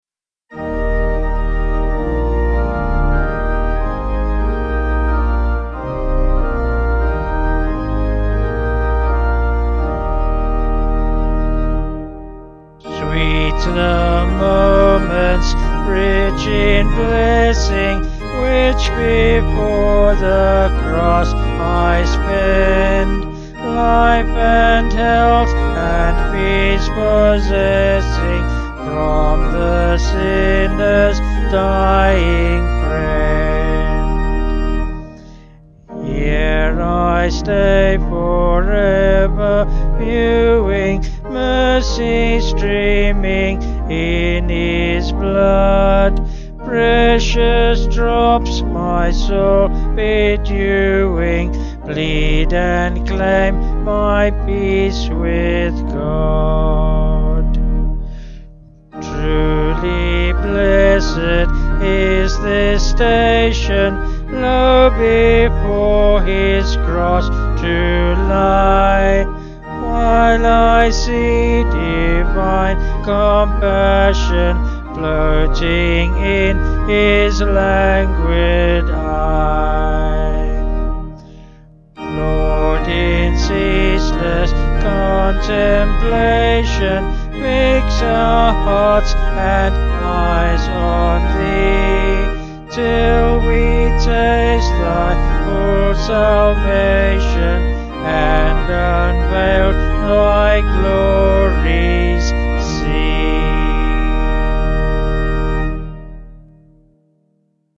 Vocals and Organ   184kb Sung Lyrics